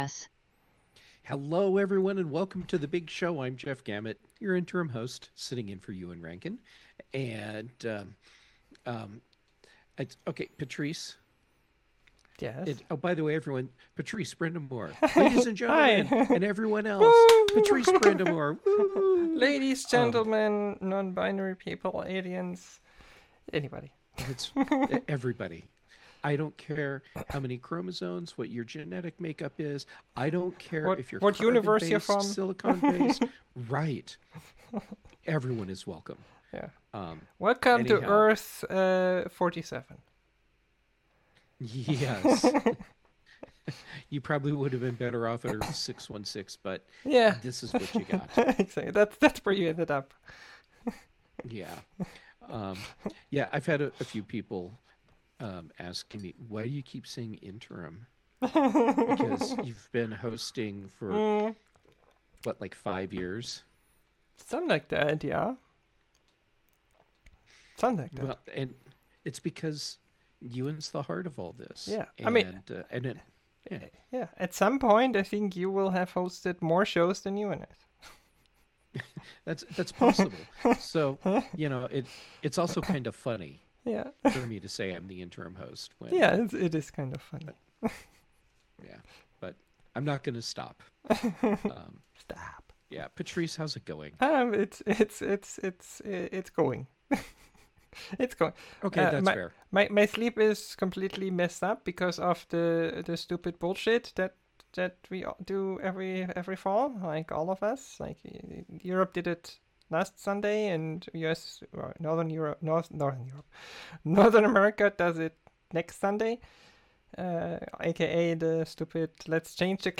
weekly discussion of the latest tech news